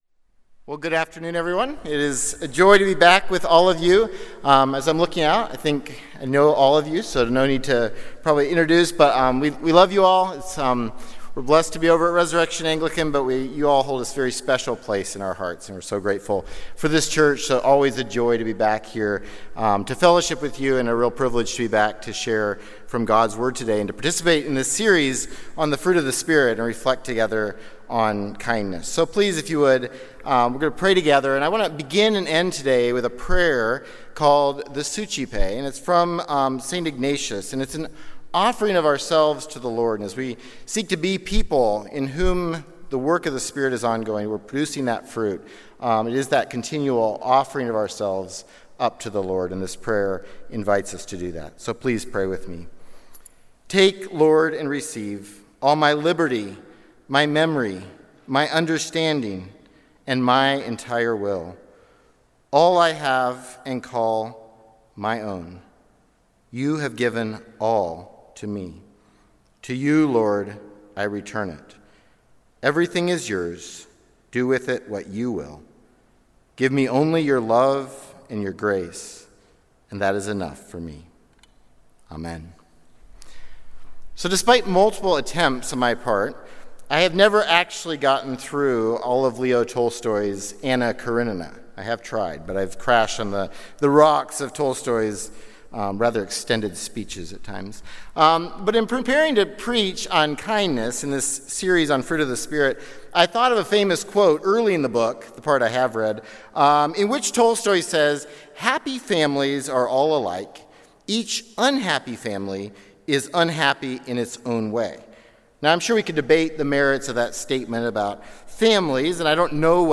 Sunday Worship–July 20, 2025
Sermons